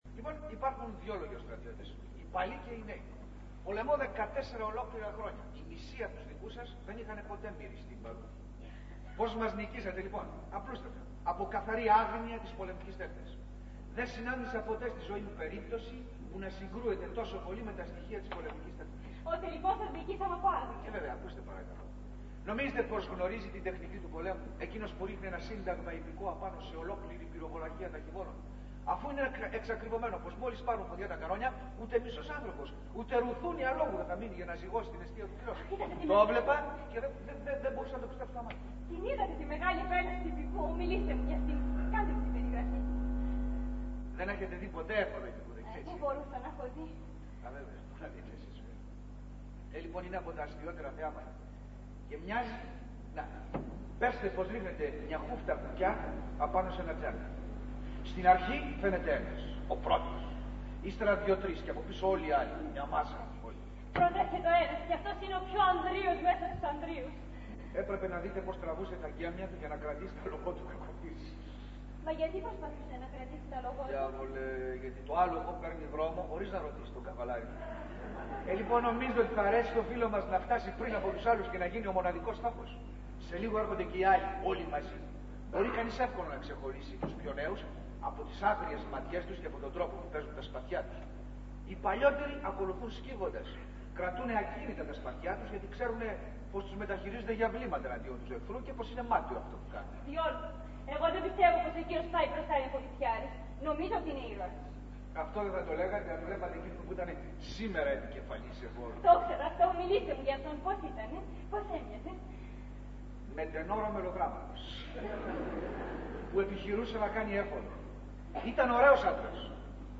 Ηχογράφηση Παράστασης
Αποσπάσματα από την παράσταση
sound 2'58'', Άγνωστος-Ράινα